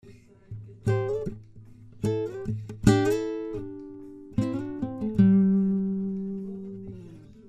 guitarra criolla mayor do folclore